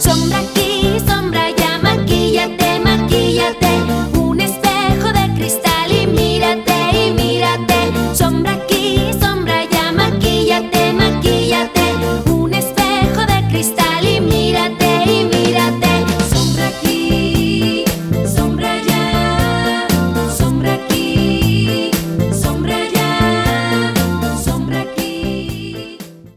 In 2007, she released another new children's album.